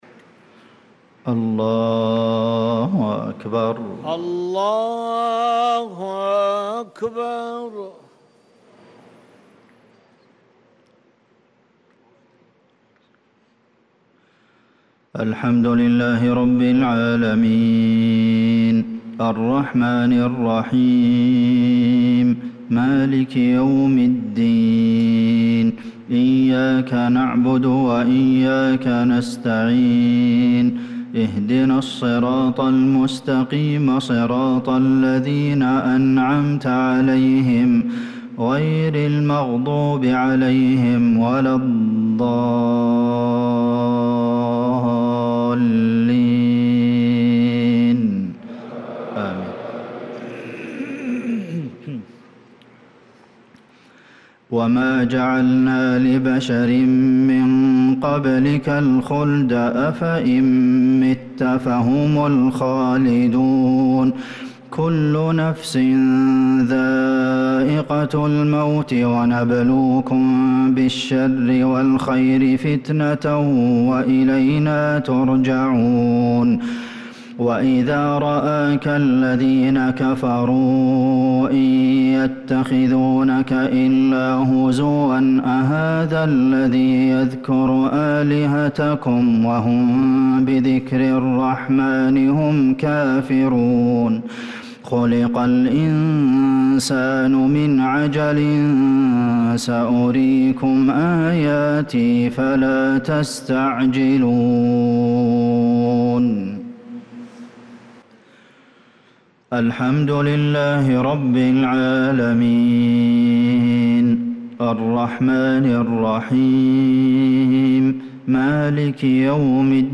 صلاة المغرب للقارئ عبدالمحسن القاسم 27 محرم 1446 هـ